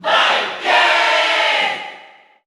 Category: Crowd cheers (SSBU) You cannot overwrite this file.
Ken_Cheer_Italian_SSBU.ogg